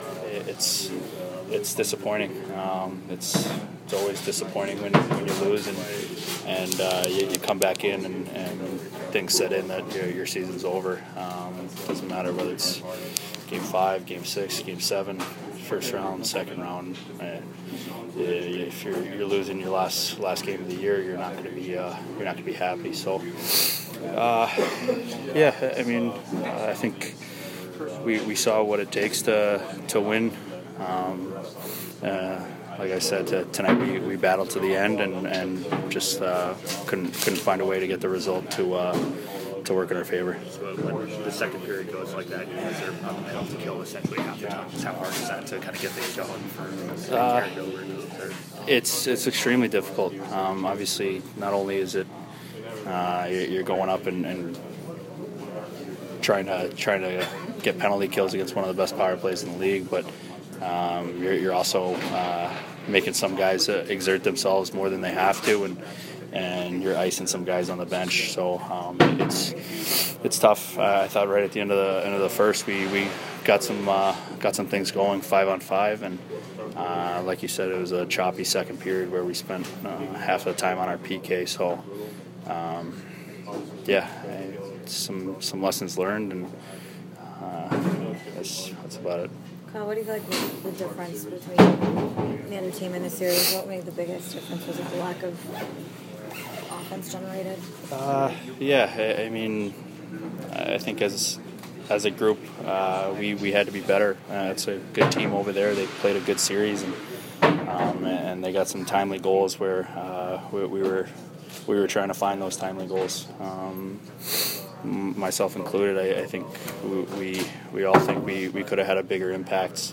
Kyle Palmieri post-game 4/21